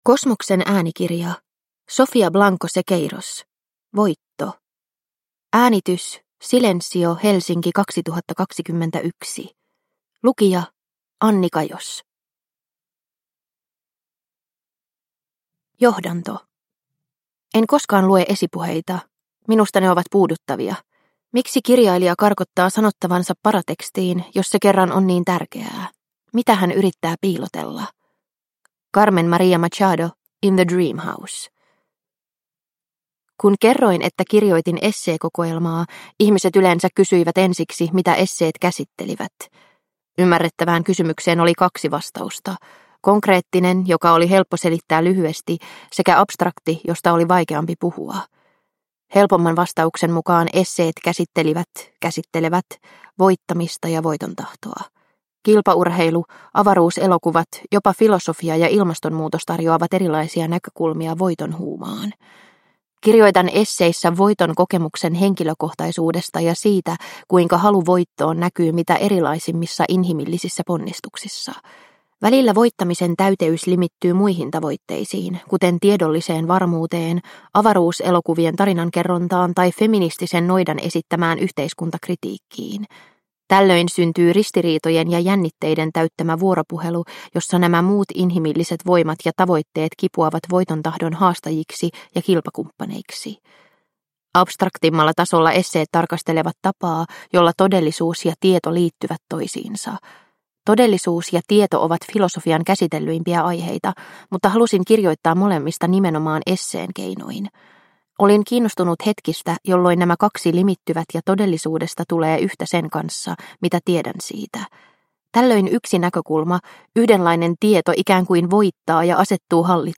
Voitto – Ljudbok – Laddas ner